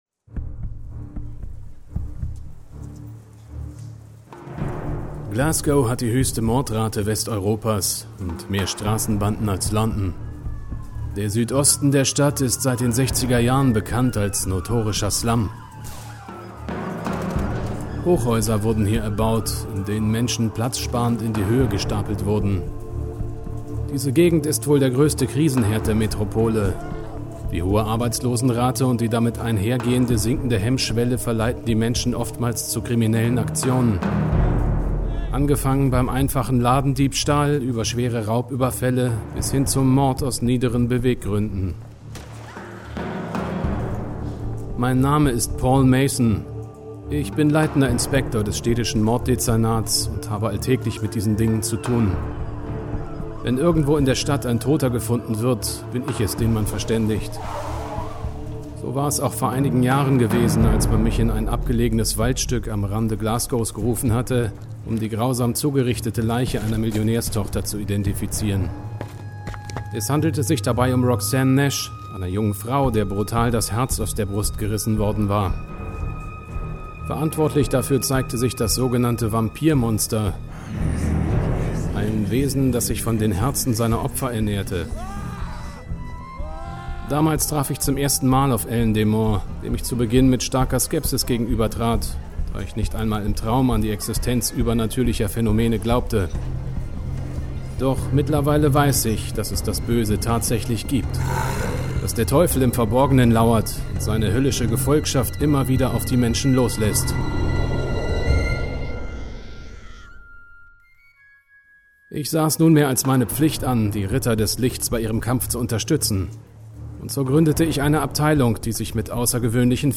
Beschreibung (Audio) Sprecher- & Cutterdemo "Paul Mayson" Sie hören eine inszenierte Audioszene, in der ich als Erzähler zu hören bin. Das gesamte Arrangement (Sprachaufnahmen, Geräusche, Effekte) wurde von mir erstellt, digital bearbeitet und unter Einsatz lizenzfreier Musik zu einer endgültigen Audioszene arrangiert.